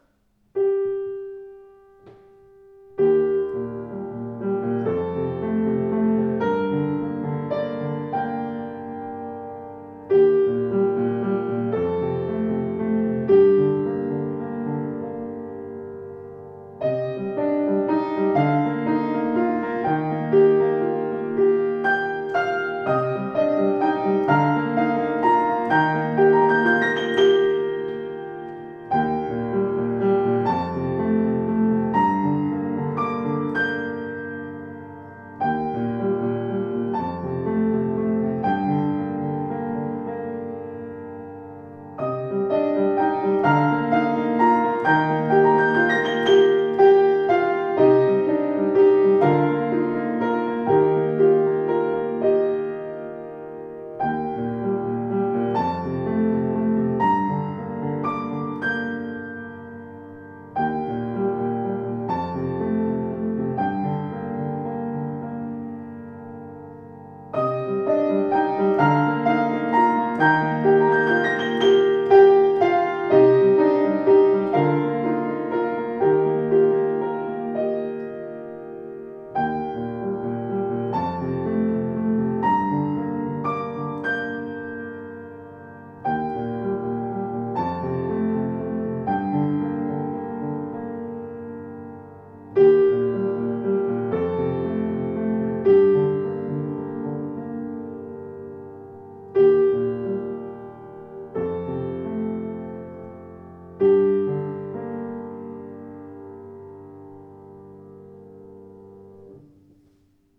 Klavier Yamaha YU10 schwarz
Schwarz poliert mit ausdruckstarkem Klang und klaren Bässen (121cm Bauhöhe wie das bekannte Modell Yamaha U1)